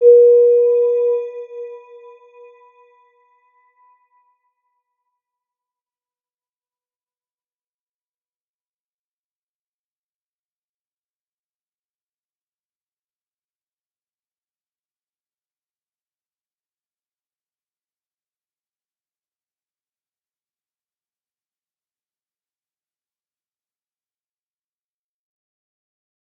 Round-Bell-B4-mf.wav